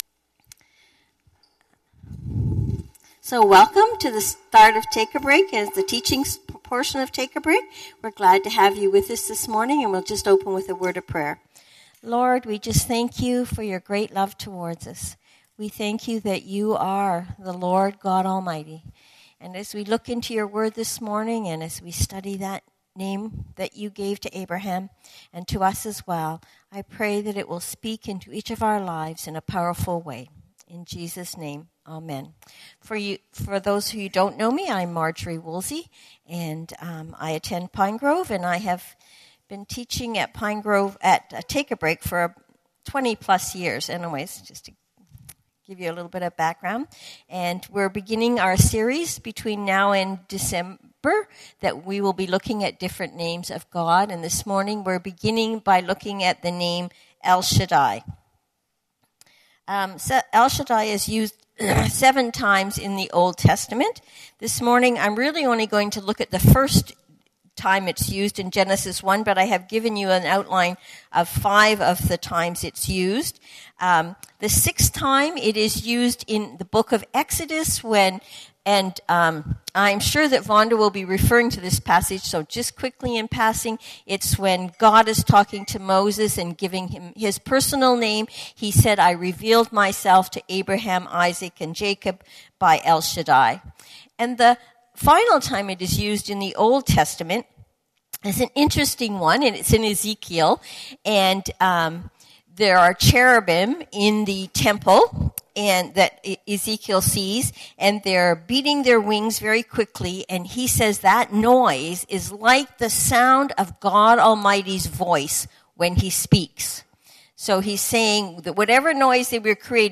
TAB (Ladies Group) Talks